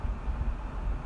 风声 " wind29
描述：风大风暴